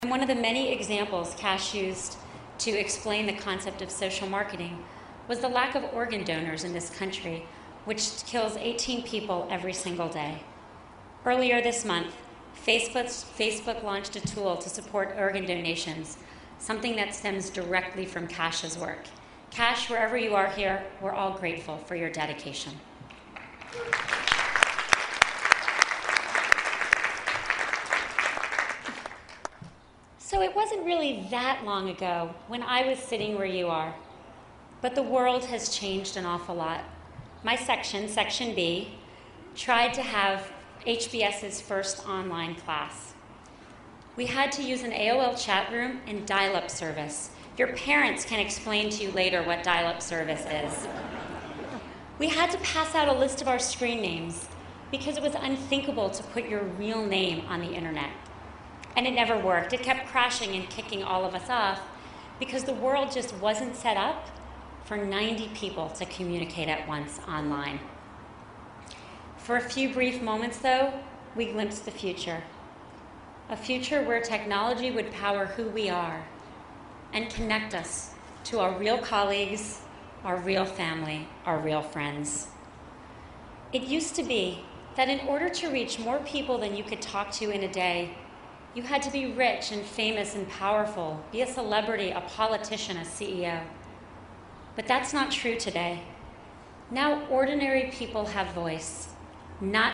公众人物毕业演讲 第177期:桑德伯格2012哈佛商学院(2) 听力文件下载—在线英语听力室